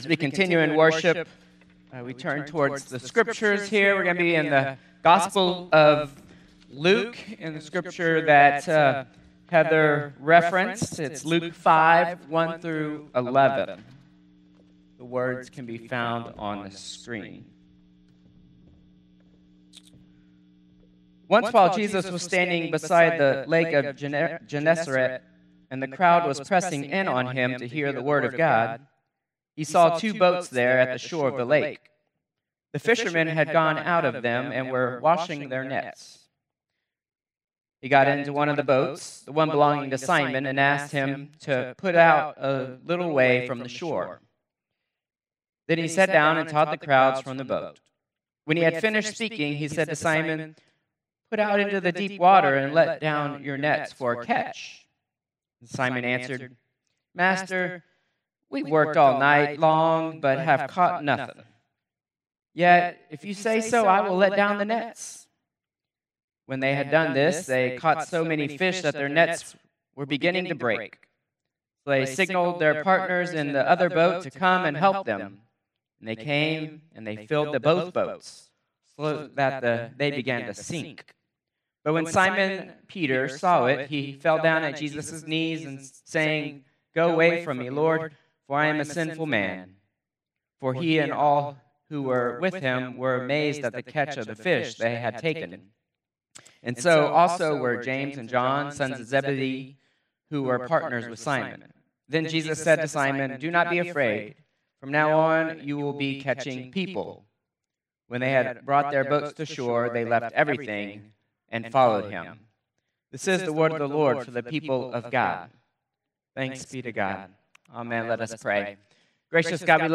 Contemporary Service 8/10/2025